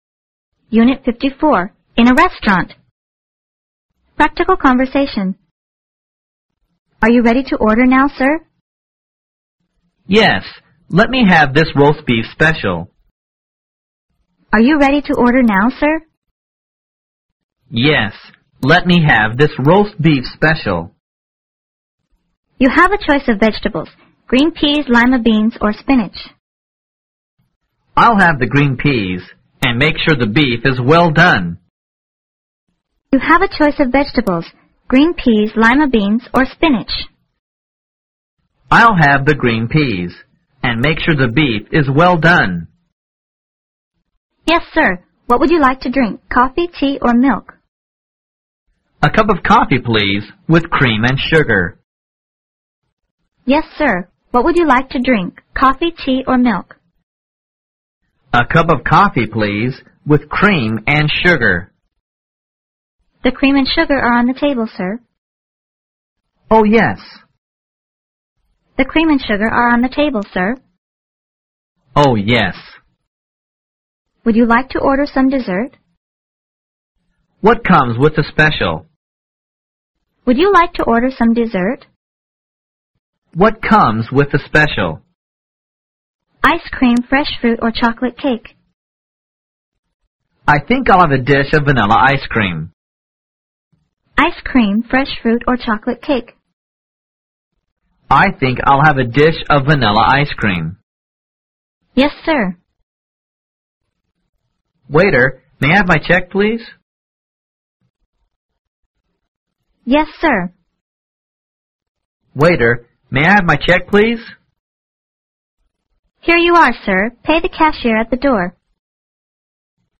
Practical consersation